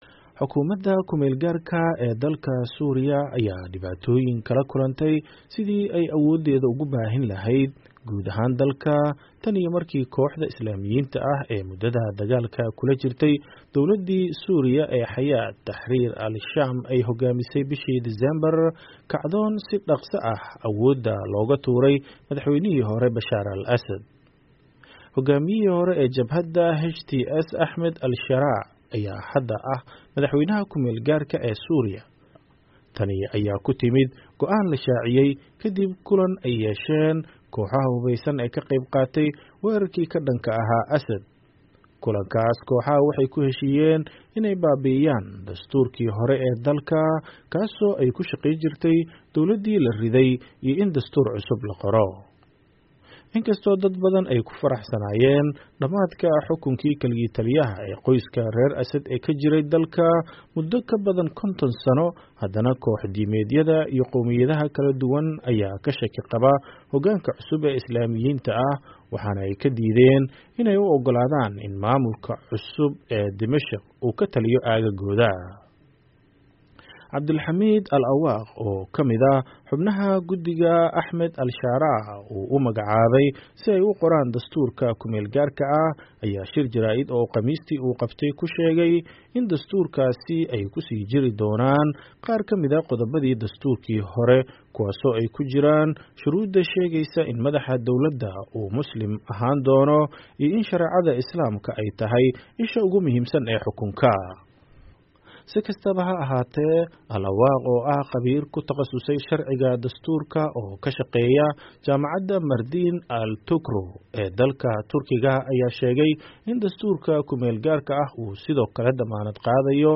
Warbixin ay arrintan ka qortay VOA